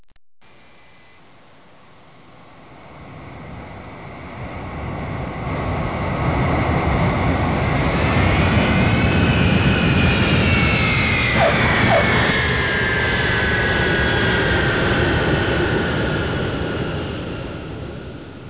دانلود آهنگ طیاره 4 از افکت صوتی حمل و نقل
جلوه های صوتی
دانلود صدای طیاره 4 از ساعد نیوز با لینک مستقیم و کیفیت بالا